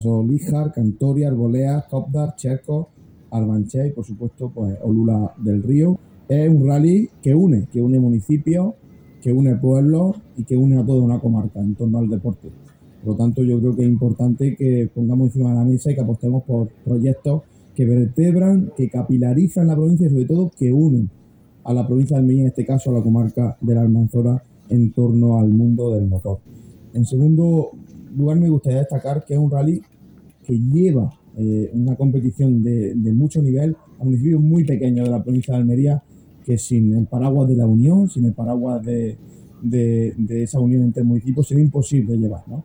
En la rueda de prensa han dado más detalles sobre esta edición el vicepresidente y diputado de Deportes, José Antonio García
24-02_rallye_almanzora_jose_antonio_garcia.mp3